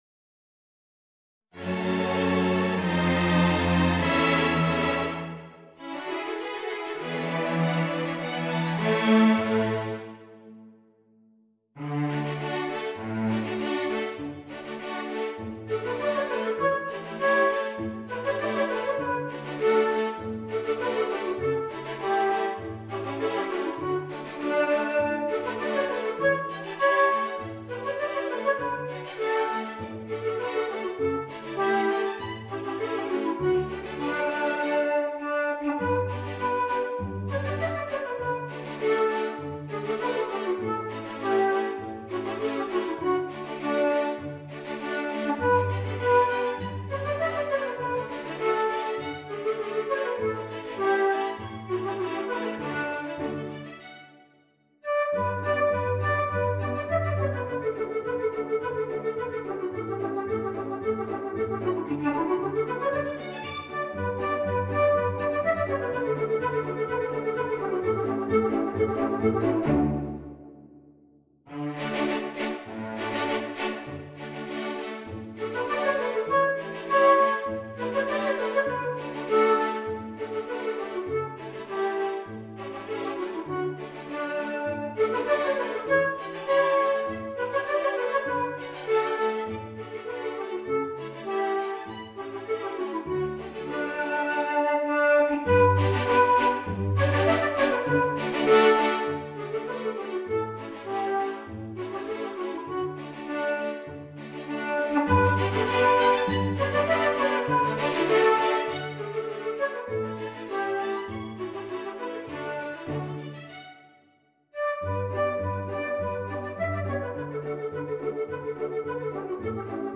Voicing: Bassoon and String Orchestra